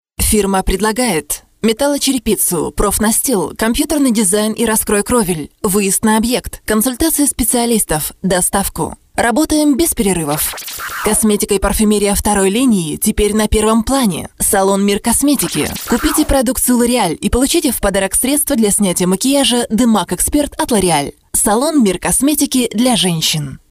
Sprecherin russisch ukrainisch.
Sprechprobe: Werbung (Muttersprache):